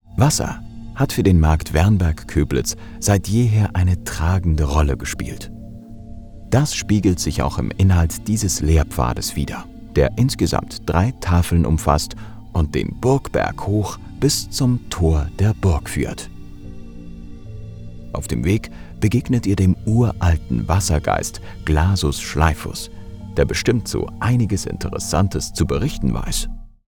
Natürlich, Zuverlässig, Freundlich, Kommerziell, Warm
Audioguide
From his own studio, he offers high-end audio quality.